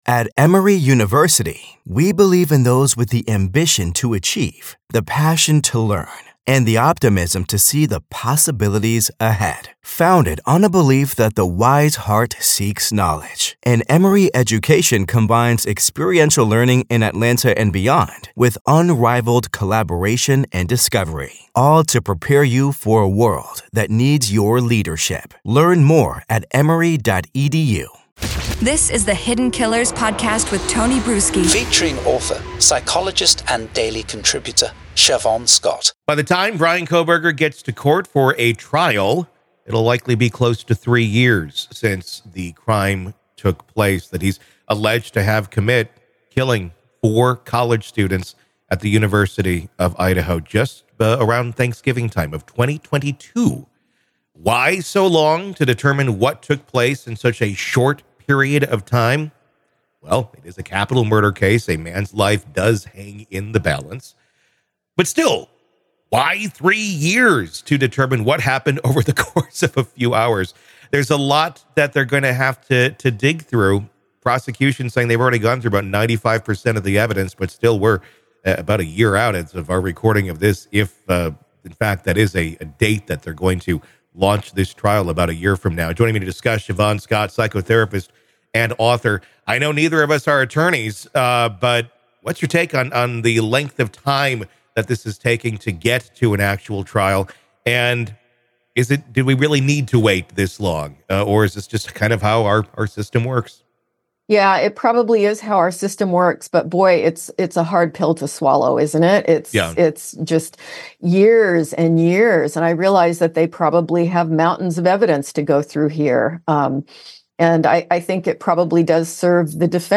• Discussion on the potential for a venue change and its implications.